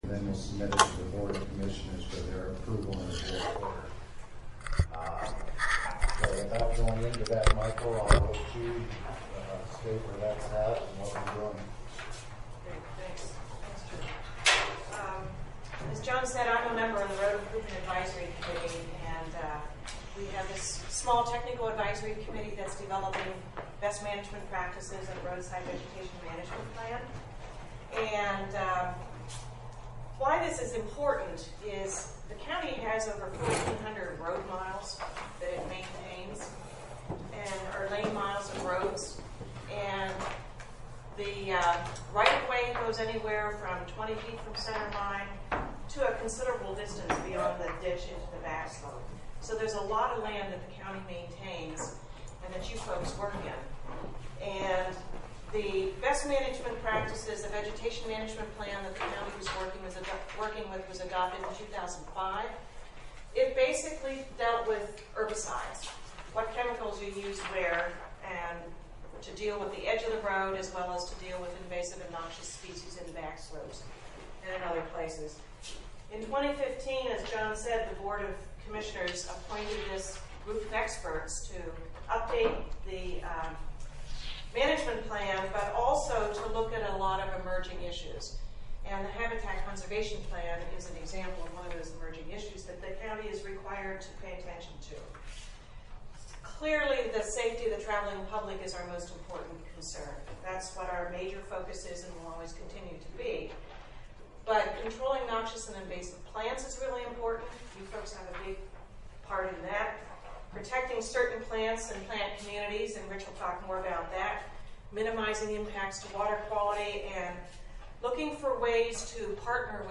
HCP Presentation by USF and W (MP3)